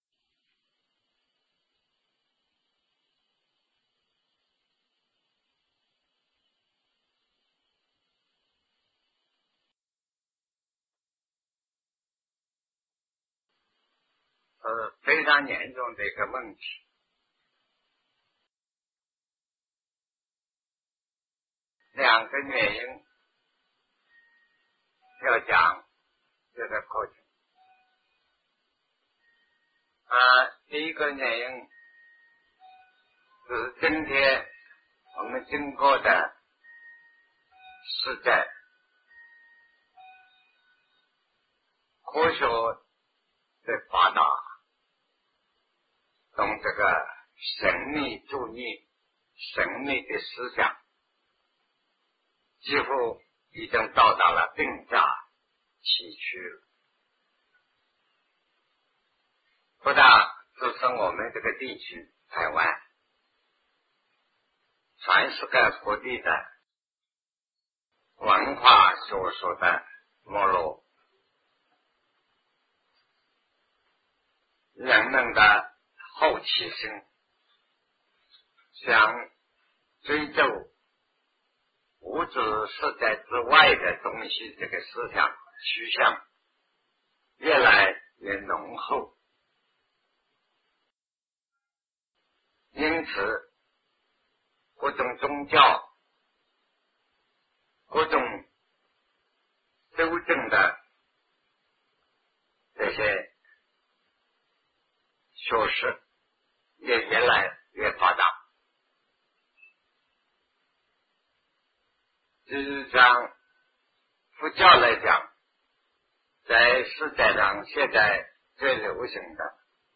为何要讲唯识 南怀瑾先生讲唯识与中观1980代初于台湾001(上)